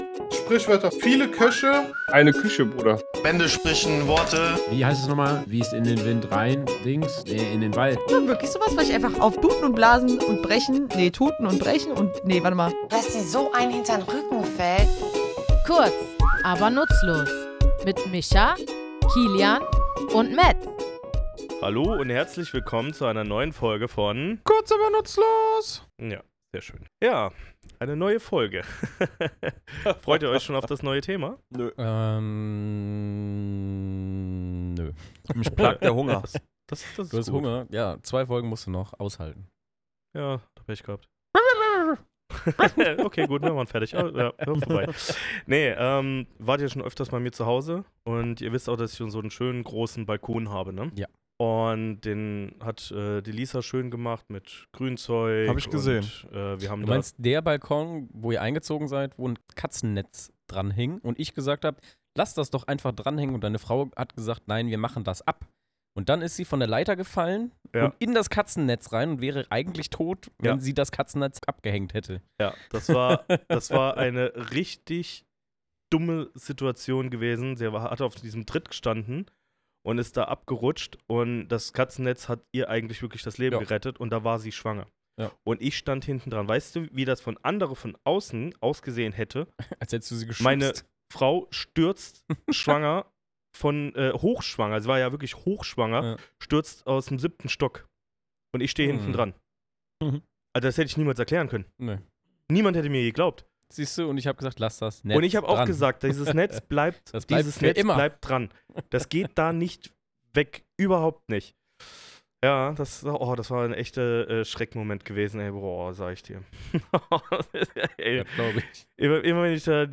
Was bedeutet dieser merkwürdige Satz, und warum muss ausgerechnet eine Maus den Faden abbeißen, wenn etwas unumstößlich ist? Wir, drei tätowierende Sprachfans, graben in unserem Tattoostudio tief in der Herkunft dieser Redensart – und stoßen auf alte Schneiderwerkstätten, tierische Bilder und die sprachliche Kunst, Tatsachen auf den Punkt zu bringen.